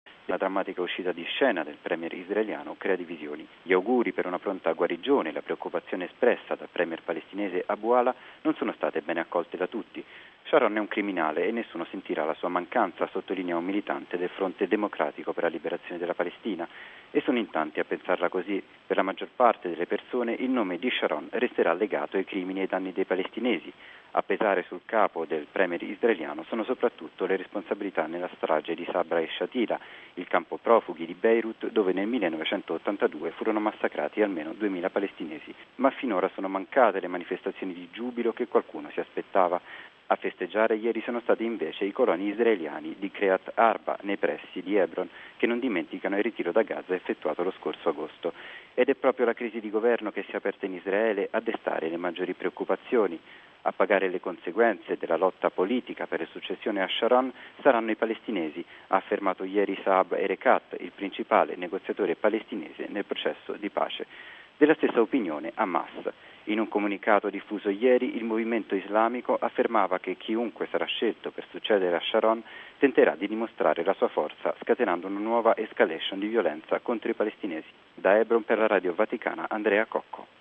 Ma le posizioni della società civile e politica palestinese restano discordanti. Il servizio